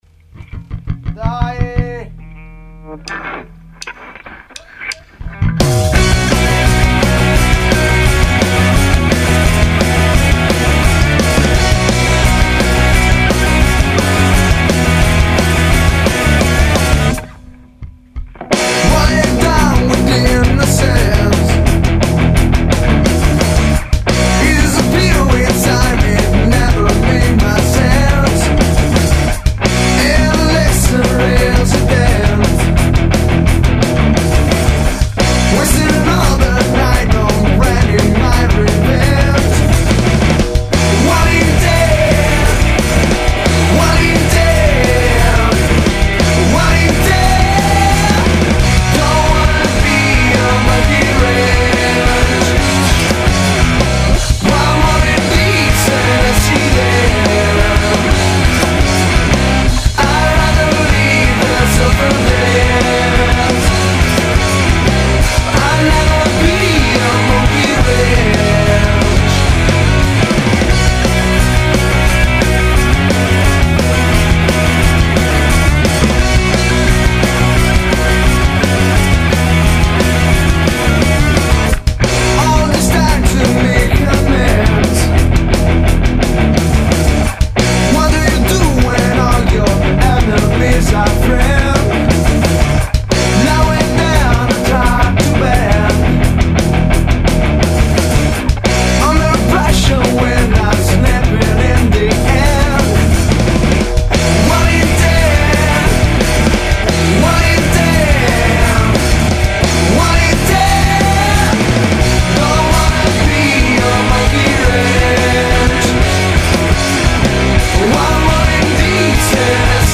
The GrungeRock Band
Chitarre, cori
Basso, cori
Batteria
Voce, chitarra acustica
all tracks recorded, mixed and mastered